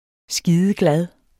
Udtale [ ˈsgiːðəˈglað ]